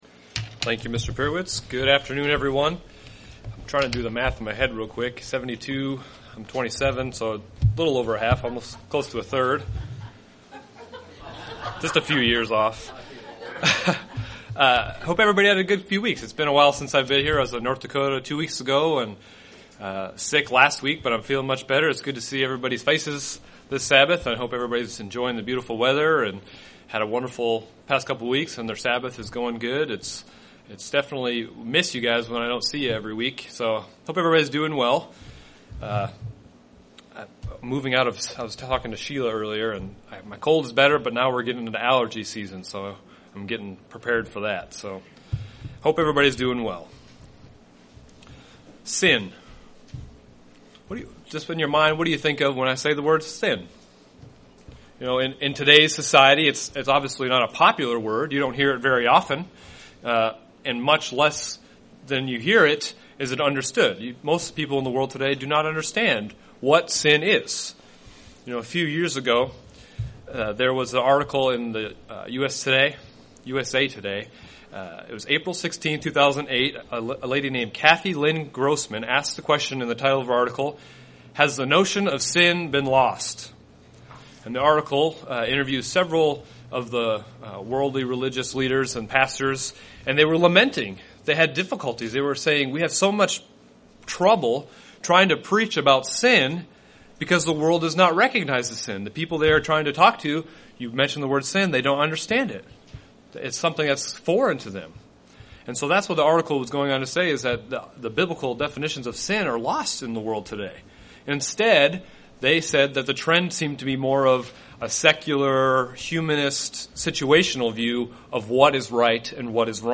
Throughout the New Testament there are more than 10 different Greek words used that translate into sin. In this part one sermon the speaker addresses 5 words and their meanings.